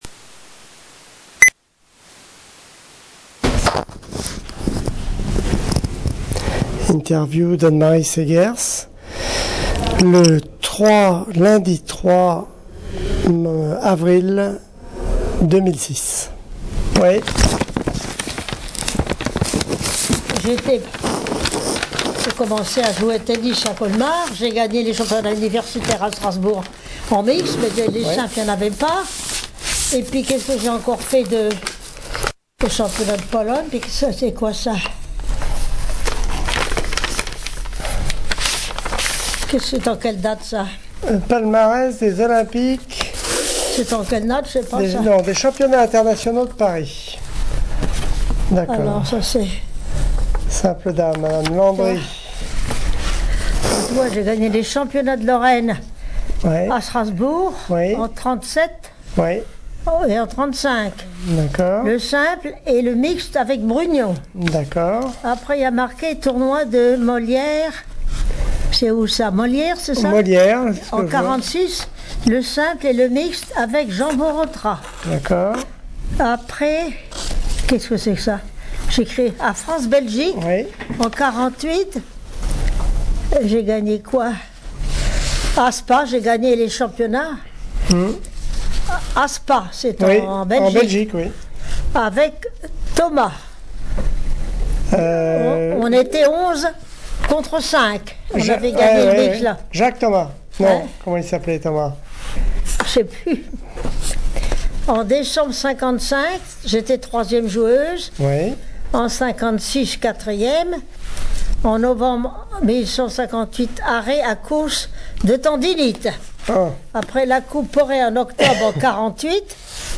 interview audio